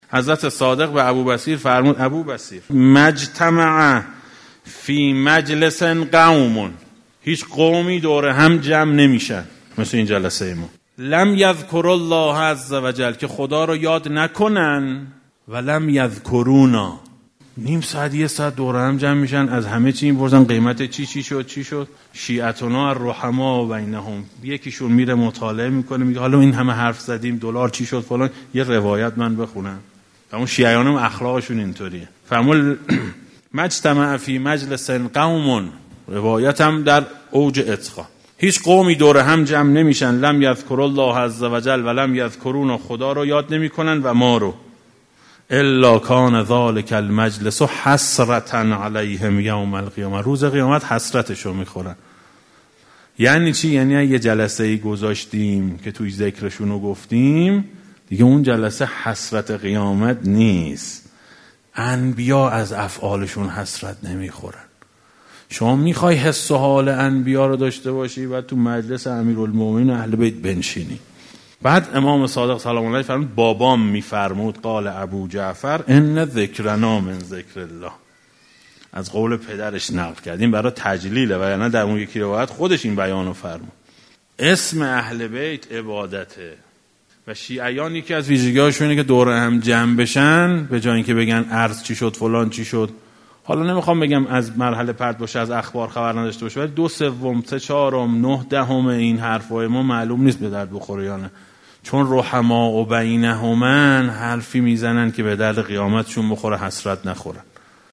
برگرفته از جلسات “درنگی در سیره امام کاظم علیه السلام”